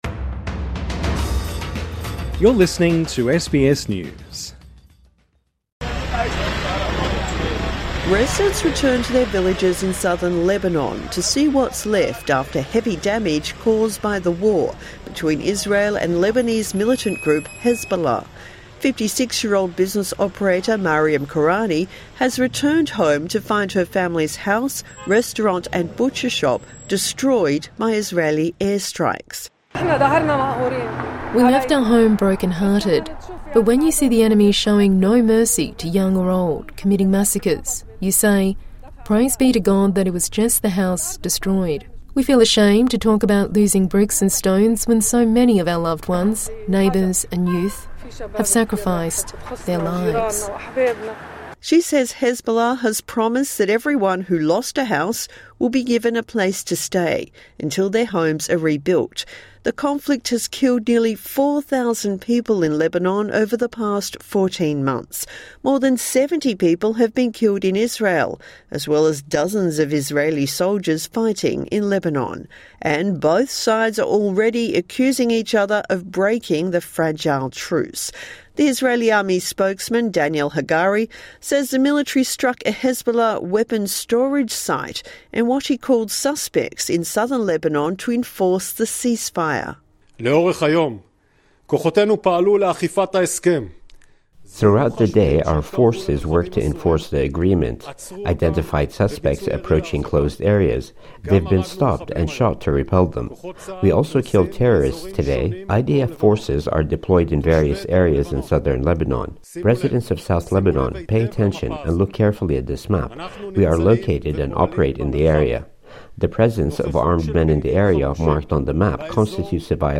(Sounds of displaced people returning to their homes in Lebanon)